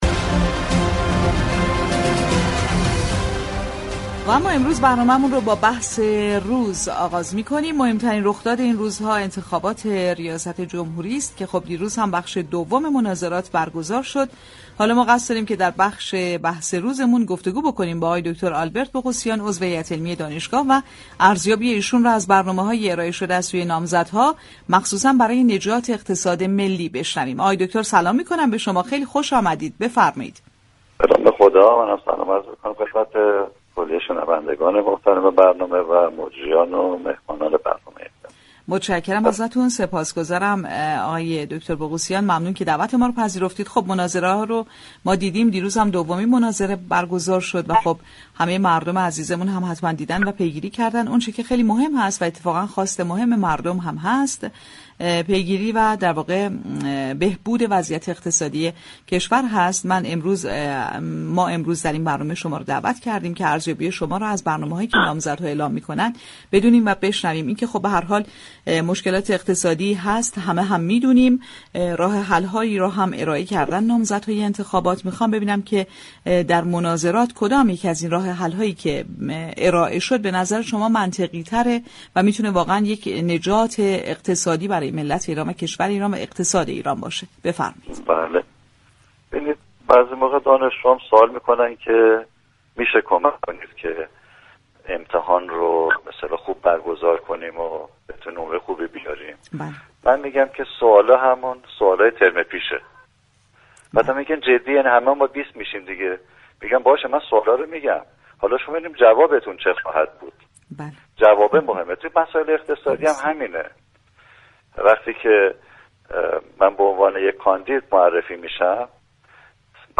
گفتگو با برنامه بازار تهران